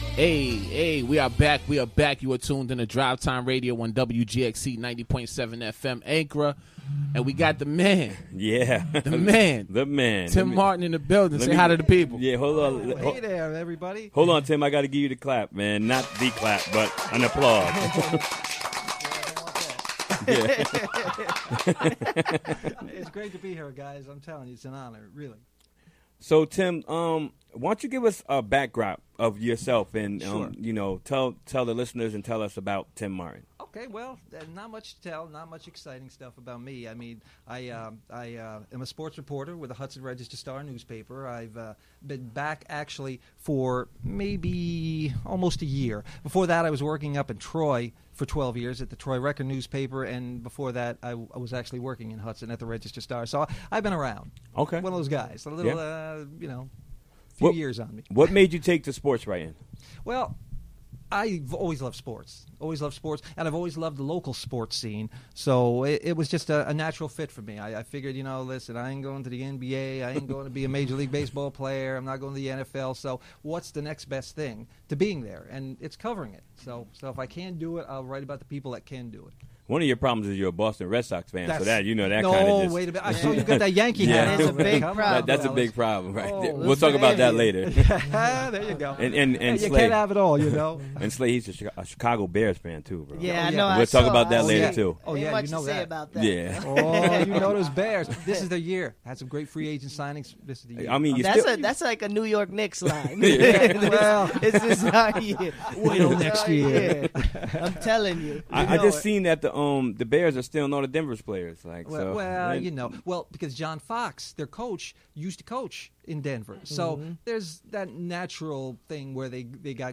Interview recorded during the WGXC Afternoon Show, Wed., Mar. 30, 2016.